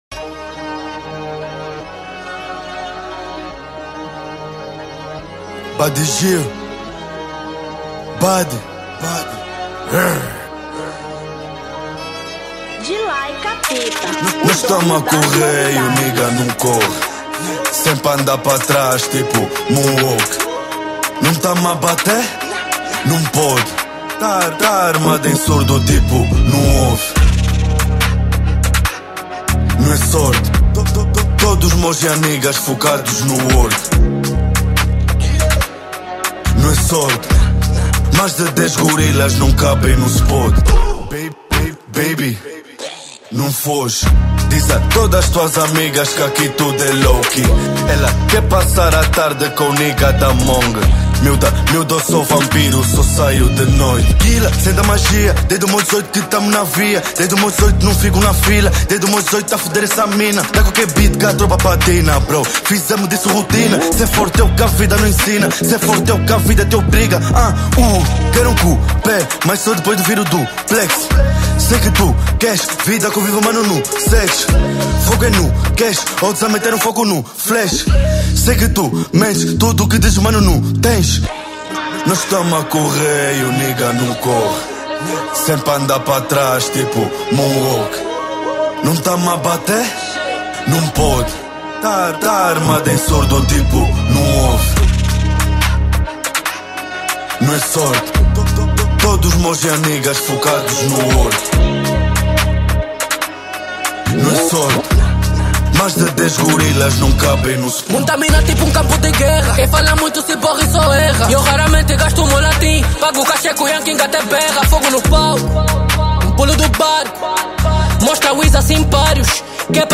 Trap 2025